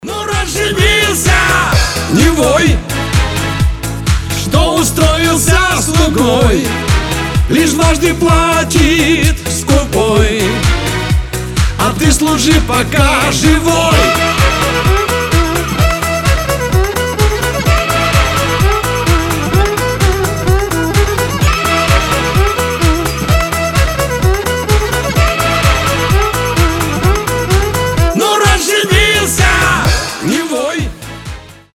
• Качество: 320, Stereo
веселые
русский шансон
смешные